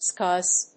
/skˈʌz(米国英語)/